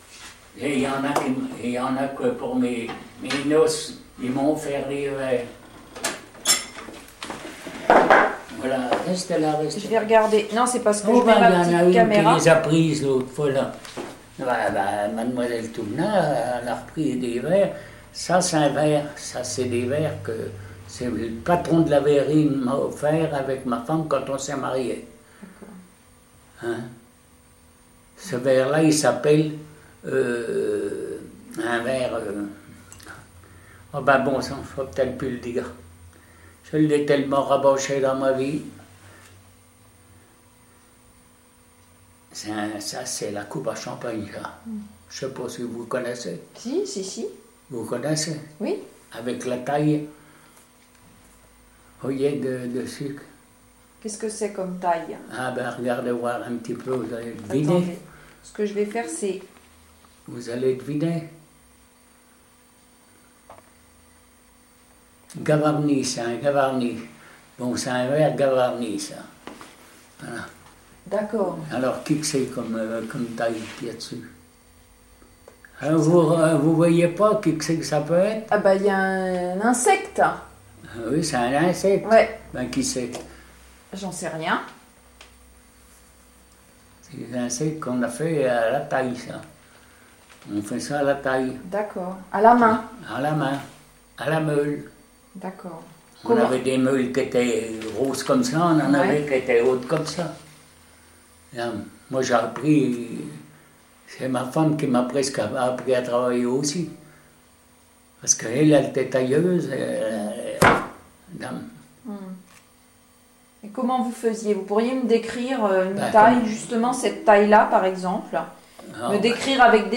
Cet article s’appuie sur une collecte audiovisuelle de la mémoire de l’industrie de la ville de Vierzon donnant lieu à la création d’un site Internet.